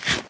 eat1.ogg